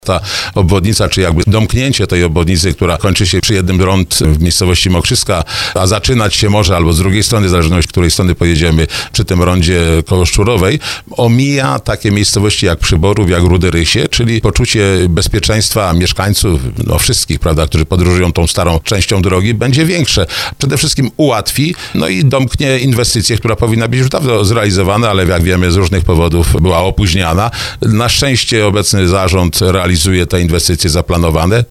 – To bardzo ważna inwestycja dla mieszkańców i całego regionu – mówi wicemarszałek województwa małopolskiego Ryszard Pagacz, który był gościem programu Słowo za Słowo.